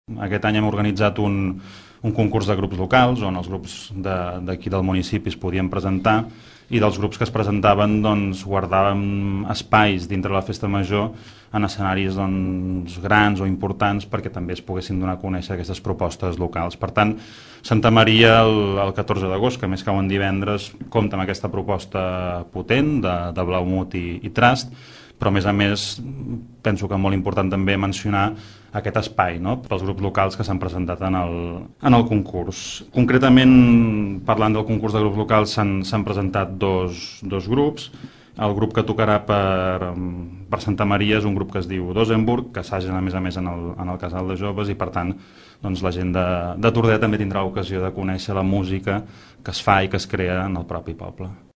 El primer grup que ho farà serà Dosenburg, que actuaran la revetlla de Santa Maria. Ho explica el regidor de Joventut, Marc Unió.